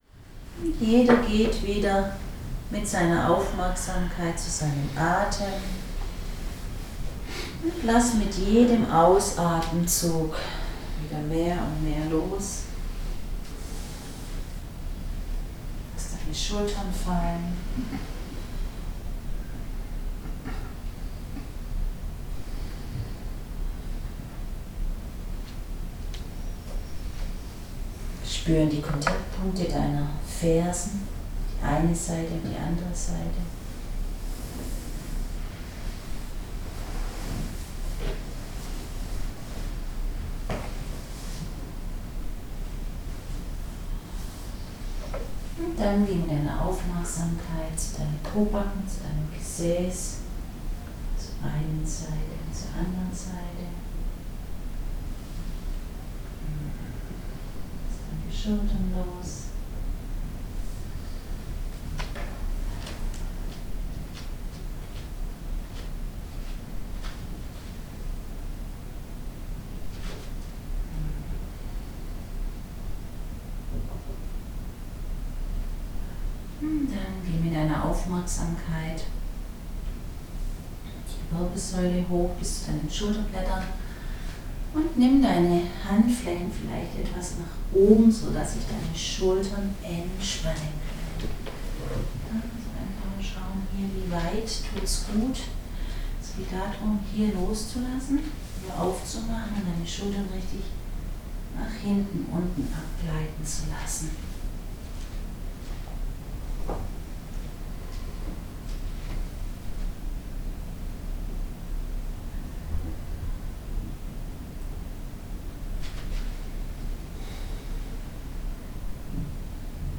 Klangschalenmeditation von 2019 vom Hotel Ifenblick
Klangschalenmeditation.mp3